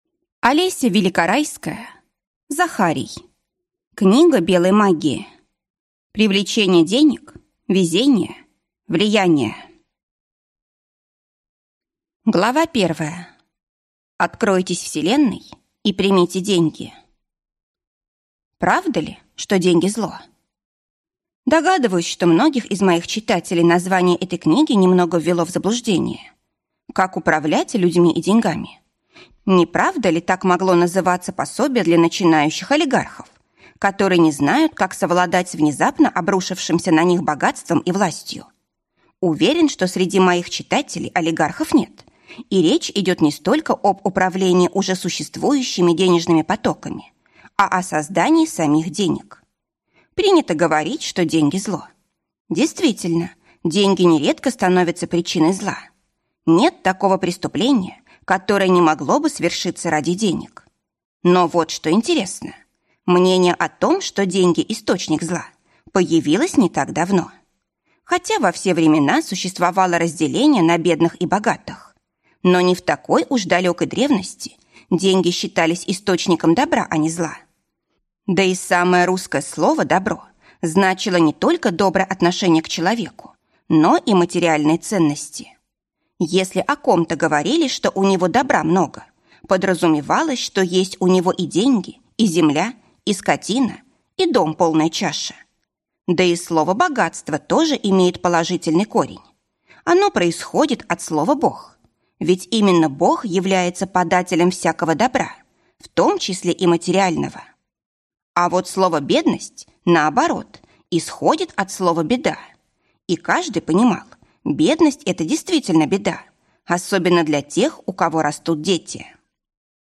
Аудиокнига Книга Белой магии. Привлечение денег, везения, влияния | Библиотека аудиокниг